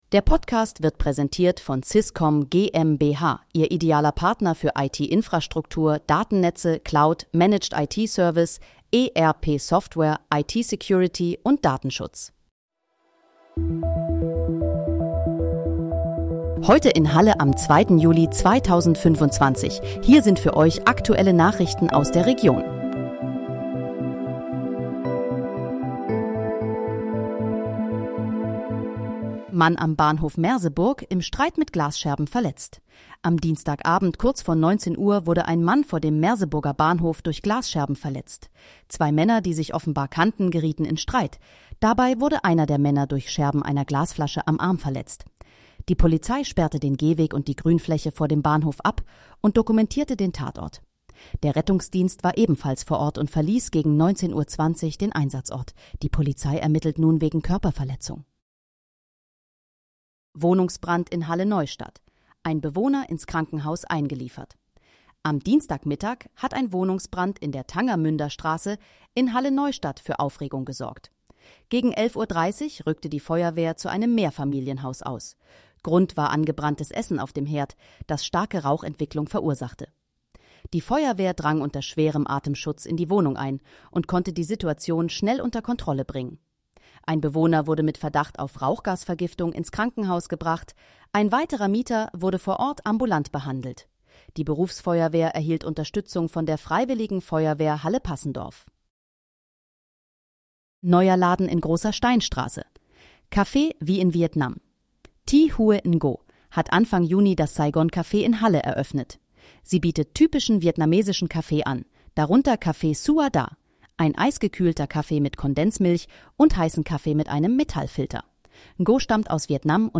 Heute in, Halle: Aktuelle Nachrichten vom 02.07.2025, erstellt mit KI-Unterstützung